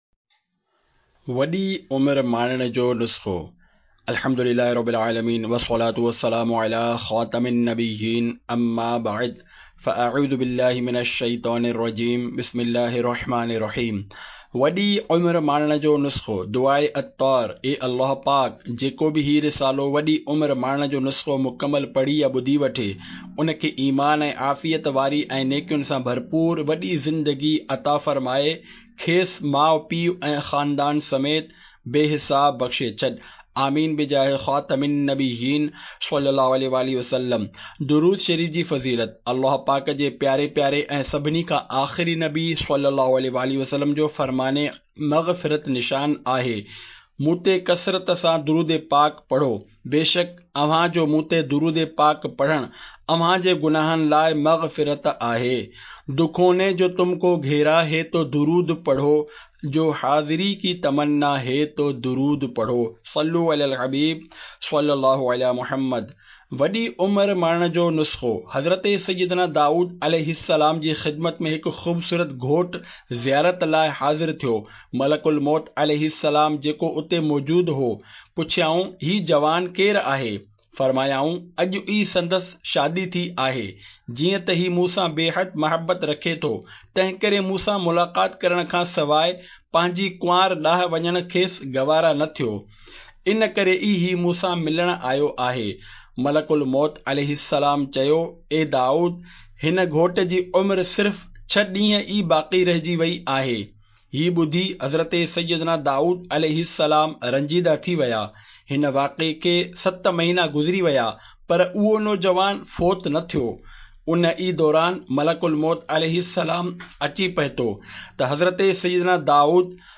Audiobook - Lambi Umer Pane Ka Nuskha (Sindhi)